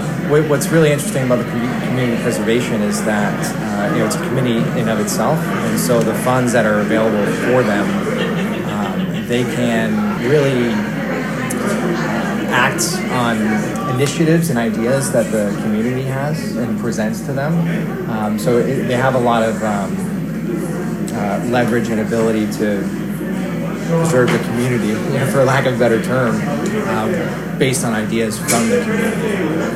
Chairman of the Finance Committee, Daniel Eddy, says that it was great to see the community agree on a vote to pass the article.